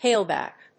アクセント・音節whále・bàck